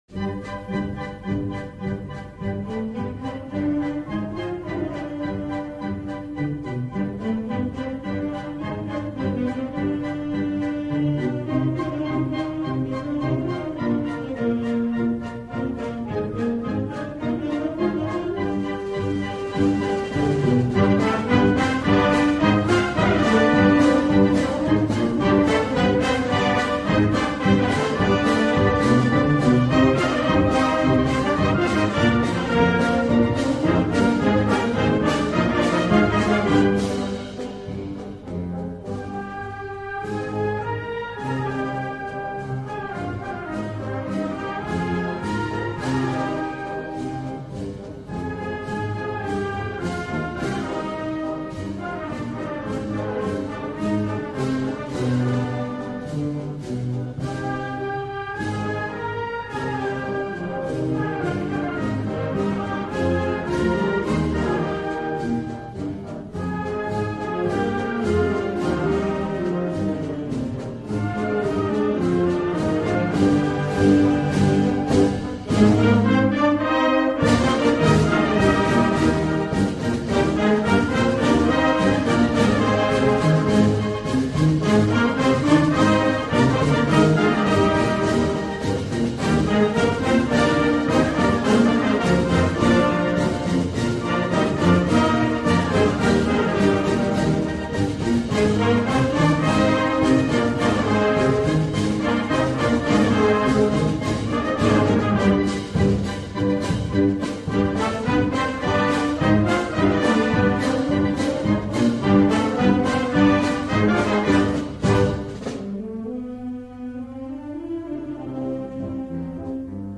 Himne
himno_ausias_march.mp3